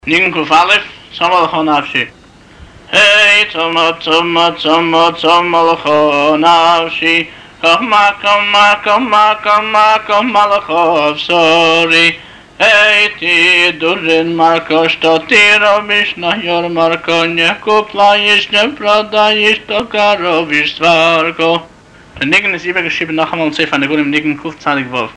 הבעל-מנגן